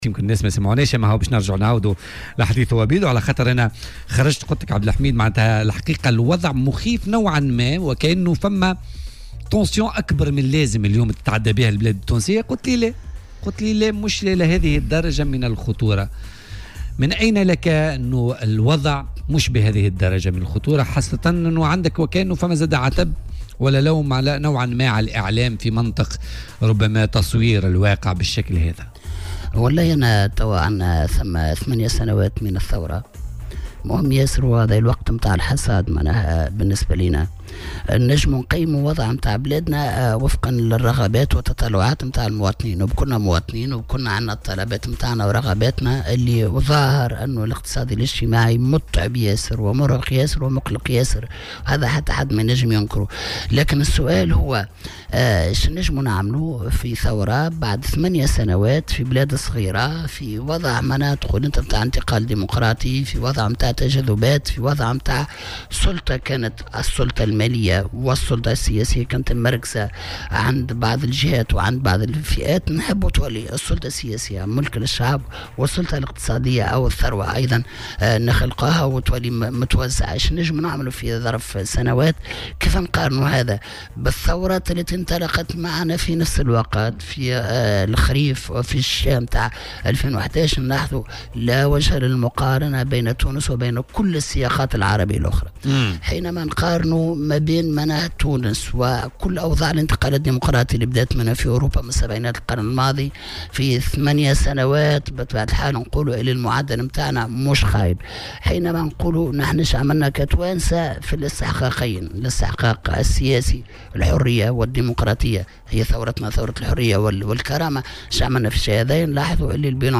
وقال ضيف "بوليتيكا" على "الجوهرة أف ام" إن هذه الثورة حققت الحرية والكرامة للمواطن التونسي رغم اقراره بوجود صعوبات اقتصادية واجتماعية مردها الوضع السياسي.